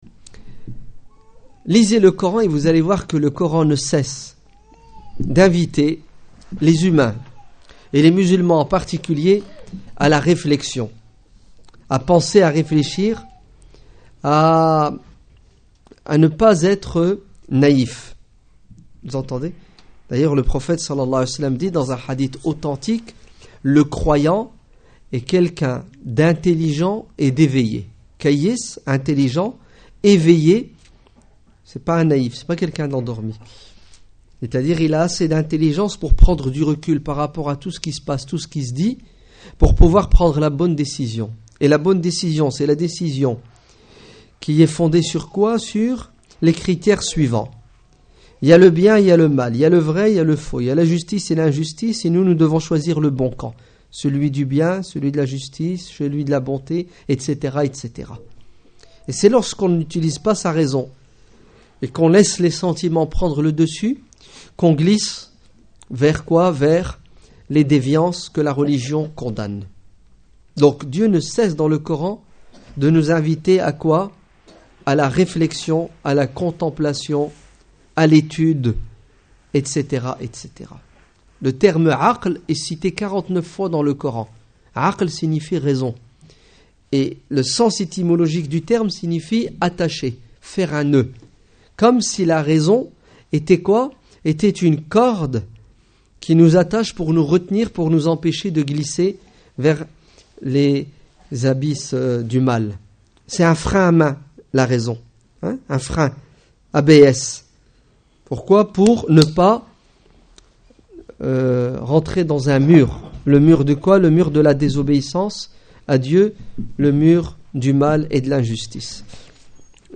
Discours du 27 avril 2012